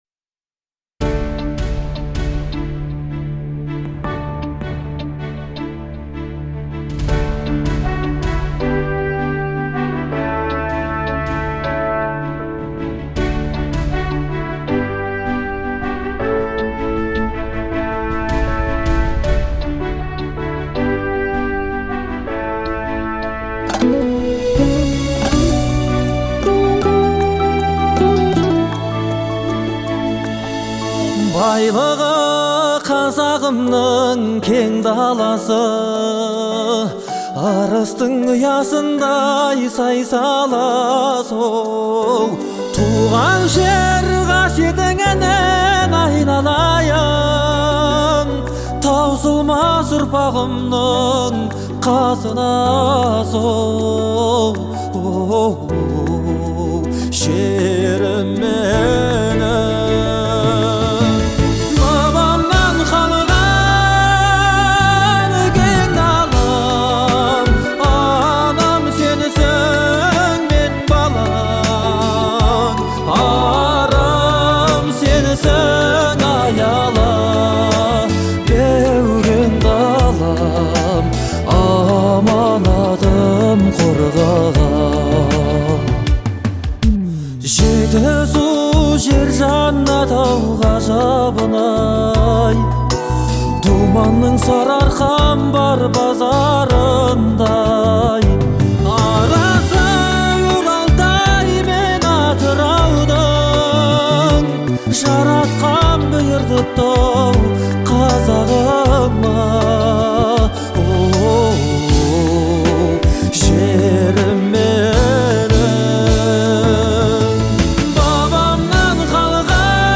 это трек в жанре казахского поп-фолка